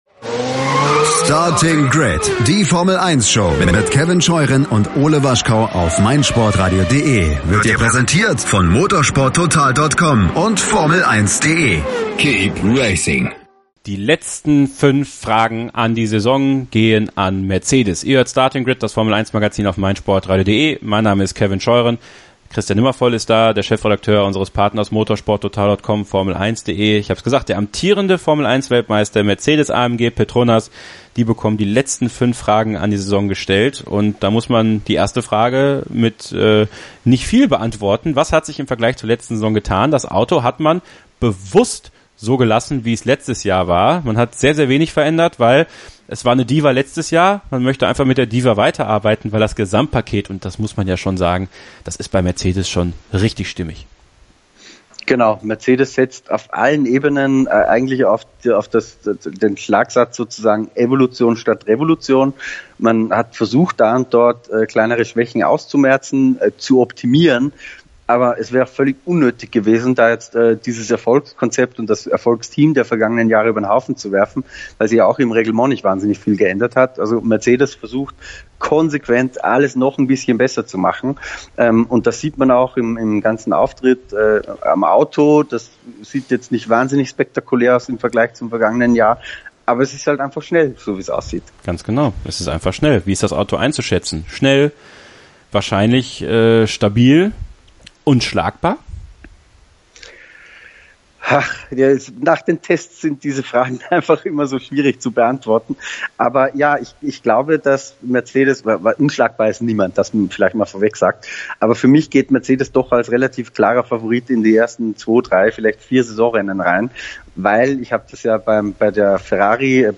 Gemeinsam werden diese ausdiskutiert und in einem lockeren Häppchen für euch zur Verfügung gestellt.